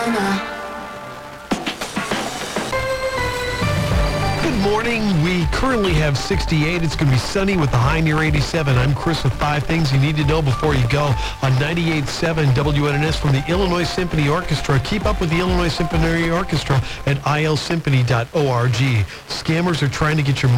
In addition to the stations shown in the local dial guide these were received this morning at about 7:50 – 8:20 AM.
here – legal ID did not include city of license)